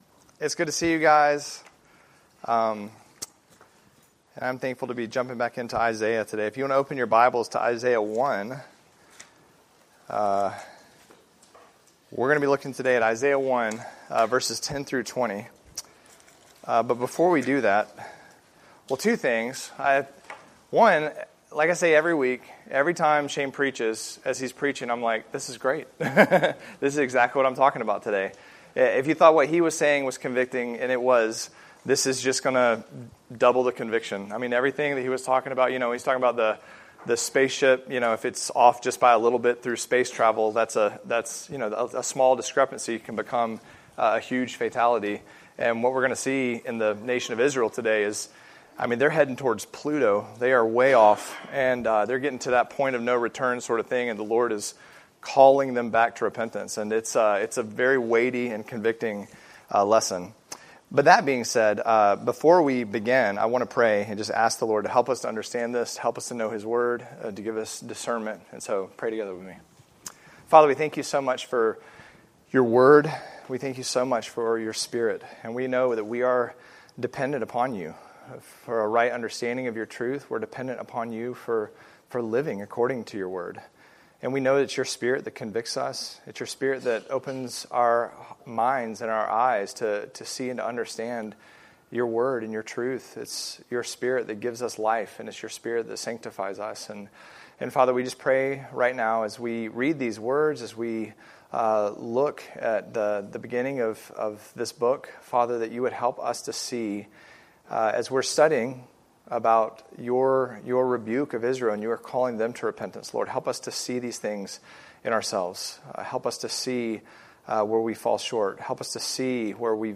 Series: Bible Studies, Sojourners Study of Isaiah